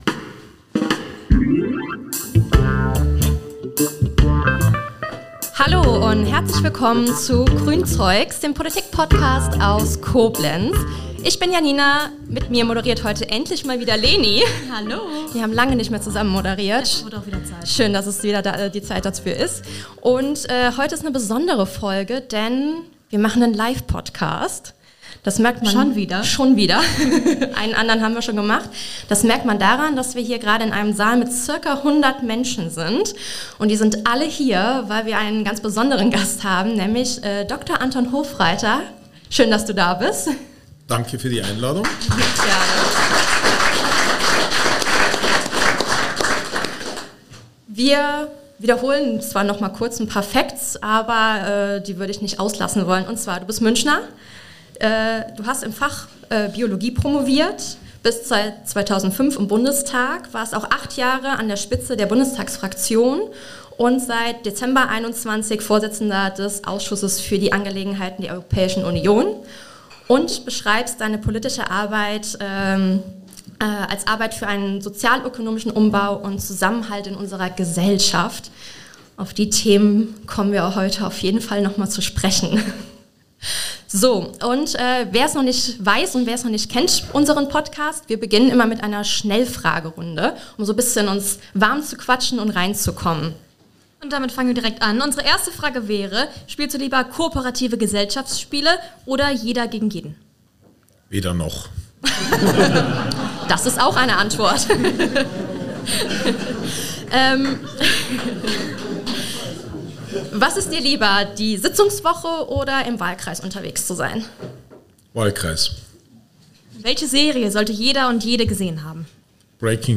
Zudem gab es zahlreiche Fragen aus dem Publikum, dem Toni Rede und Antwort stand.